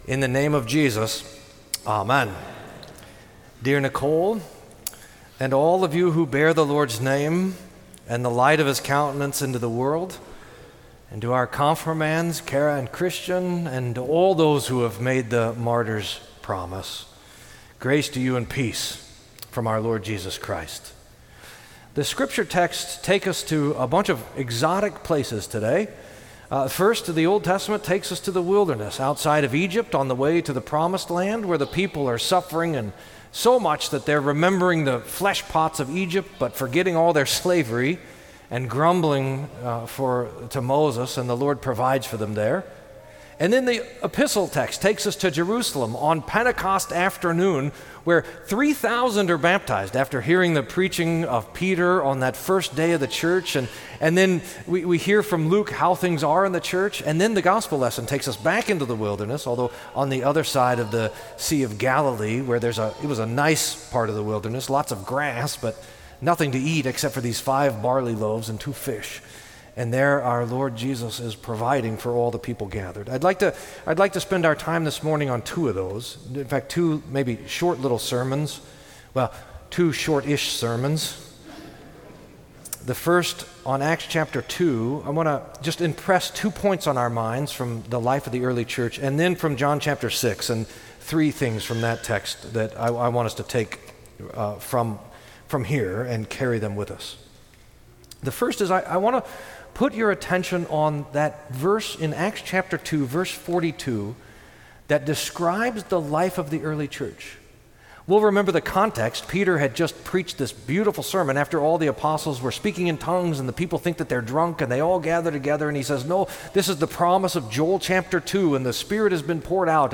Sermon for Fourth Sunday in Lent